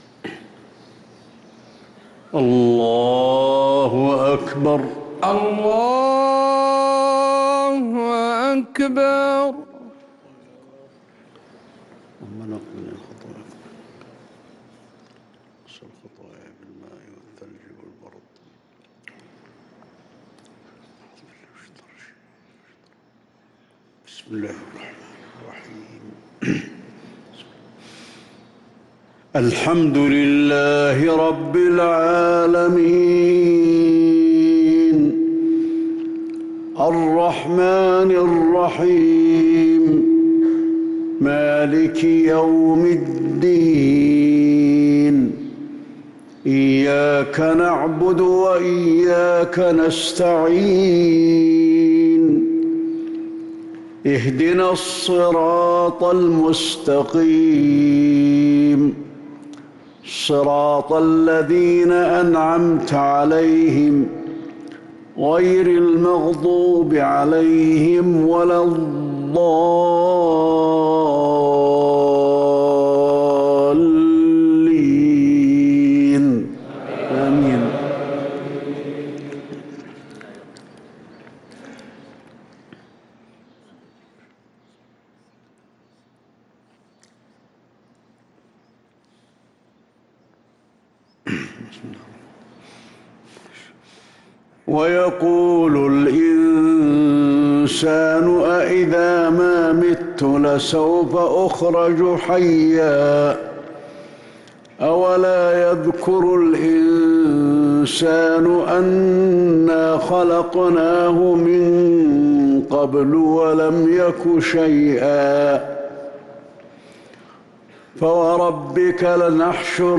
صلاة المغرب للقارئ علي الحذيفي 3 شوال 1444 هـ